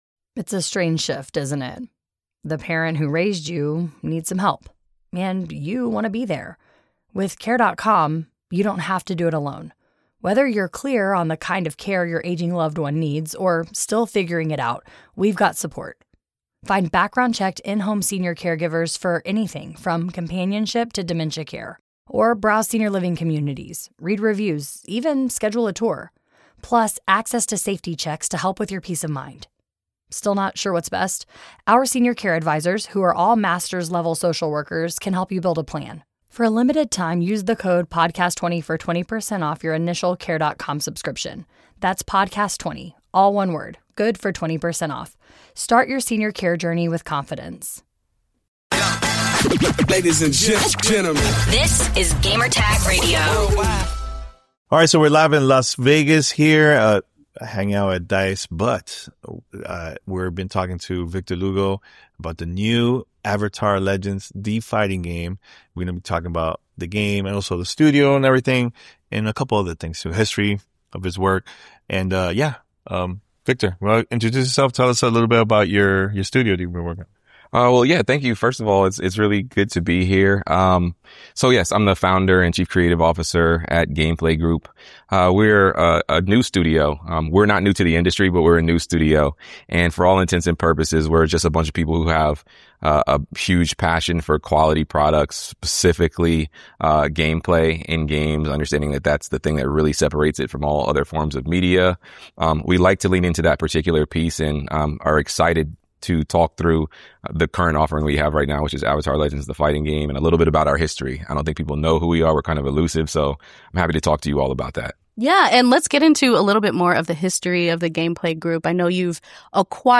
Avatar Legends: The Fighting Game Interview